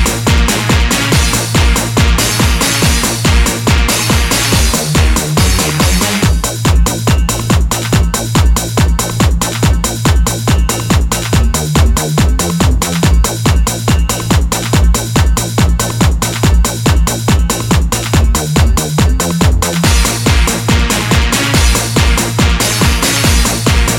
no Backing Vocals Dance 3:44 Buy £1.50